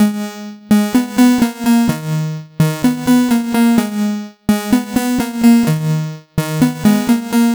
Sinisteria Ab 127.wav